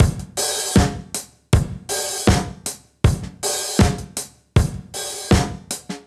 Index of /musicradar/sampled-funk-soul-samples/79bpm/Beats
SSF_DrumsProc1_79-03.wav